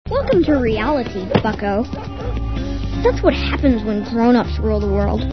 Seeing that hardly anyone else seems to be offering up sounds from Hey Arnold!, I've decided to roll my own (it helps that I have a laptop, an audio cable, a stereo attached to the digibox, and thus the means to connect the three to record audio from eps of the show).